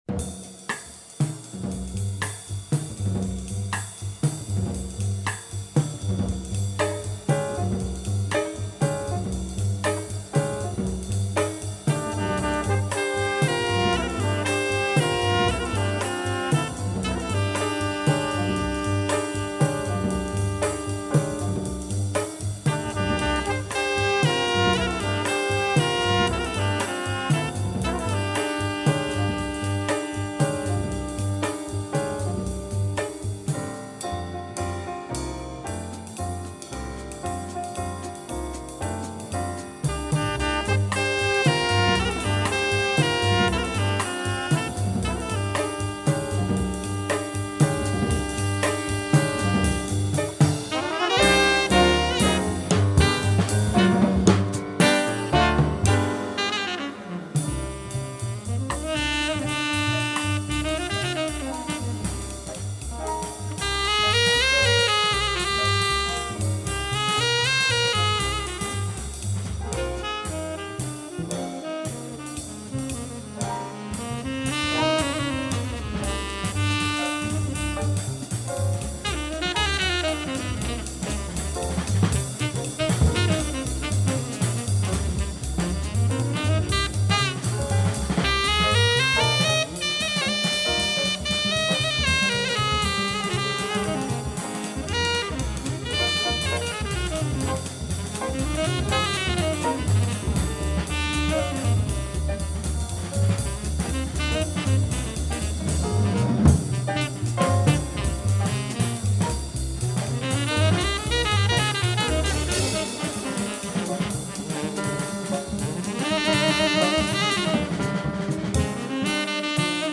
One of the best Spanish jazz albums !